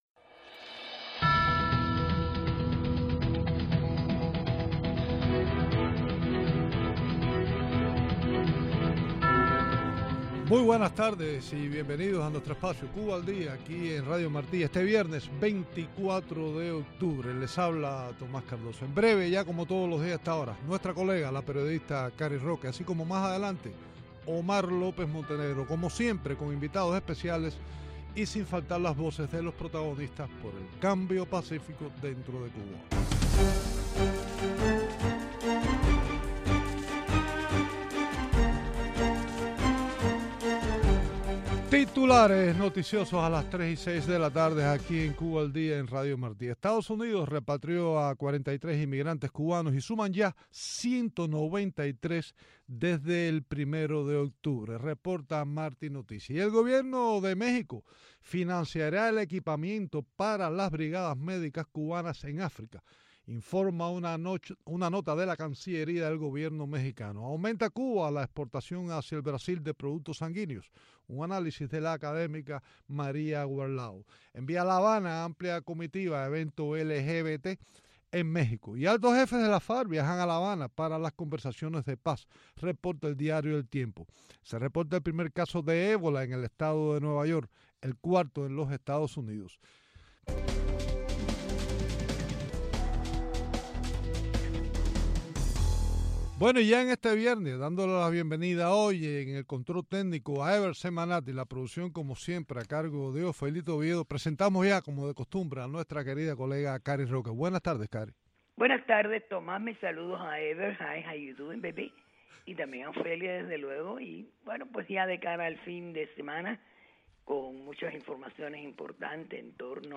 Entrevistas
En la segunda parte en vivo desde el Centro Latino Americano de no violencia en Vera Cruz, México